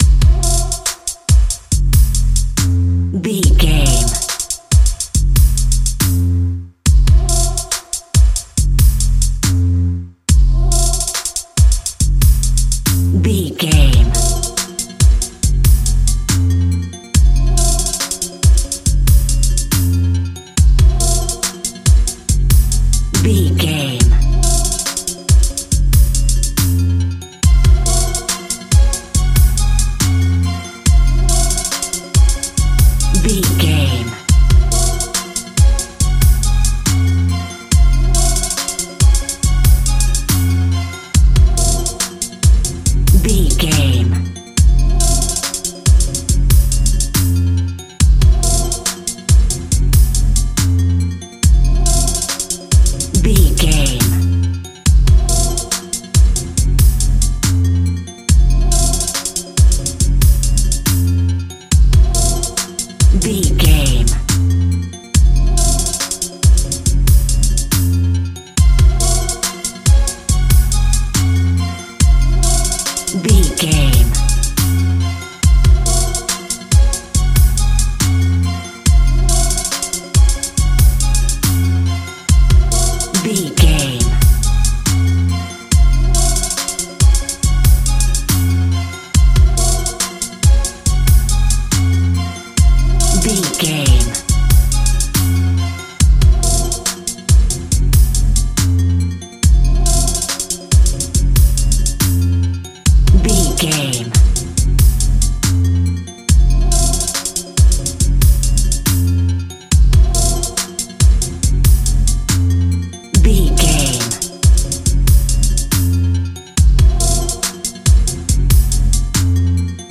Ionian/Major
eerie
epic
ominous
magical
dark
hypnotic